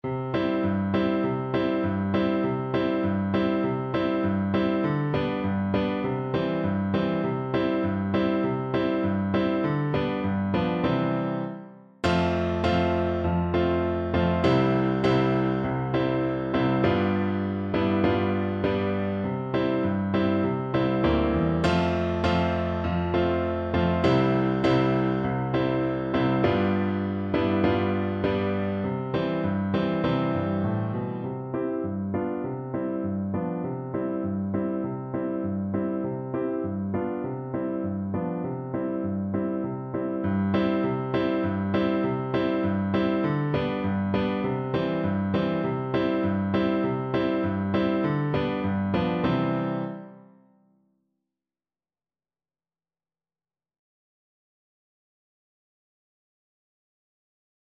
Play (or use space bar on your keyboard) Pause Music Playalong - Piano Accompaniment Playalong Band Accompaniment not yet available transpose reset tempo print settings full screen
2/4 (View more 2/4 Music)
C major (Sounding Pitch) (View more C major Music for Flute )
~ = 100 Allegro (View more music marked Allegro)